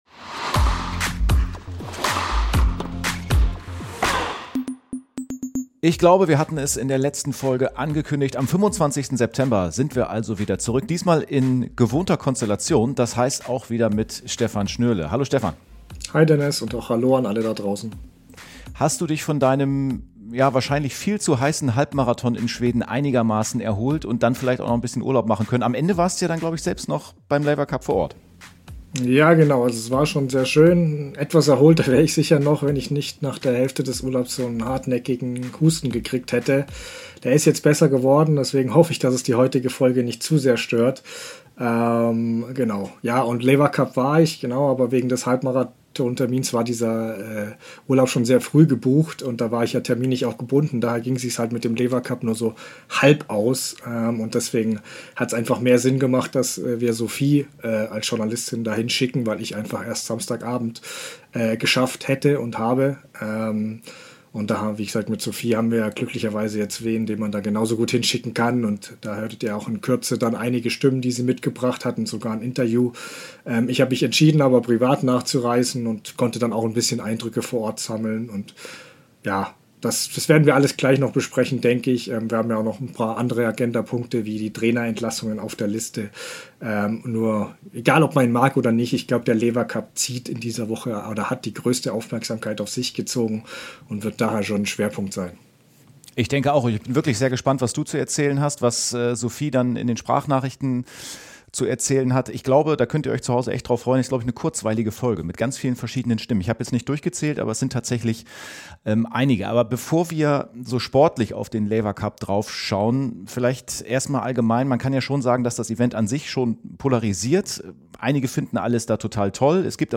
Neben ihren Eindrücke von vor Ort und einem kleinen Einblick hinter die Kulissen hat sie auch viele Stimmen von Stars wie Carlos Alcaraz, Alexander Zverev sowie ein Interview mit Jan-Lennard Struff im Gepäck.